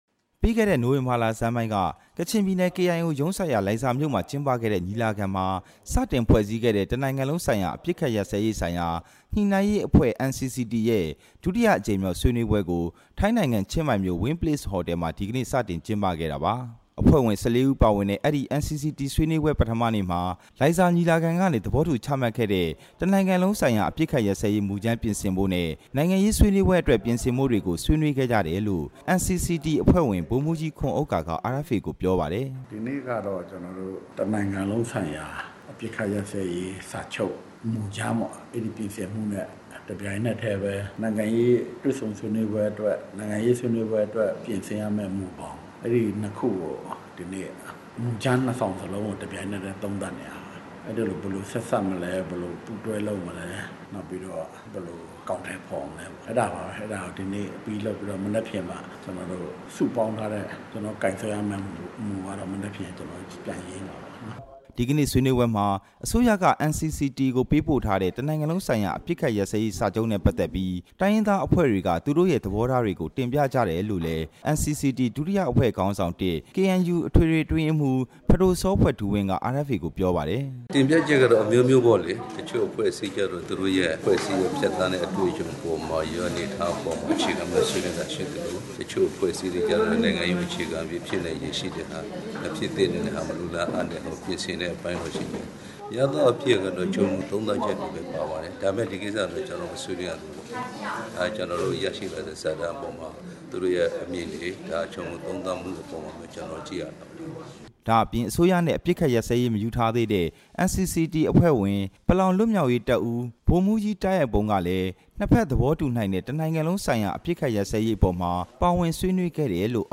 ဆွေးနွေးပွဲအကြောင်း သတင်းပေးပို့ချက်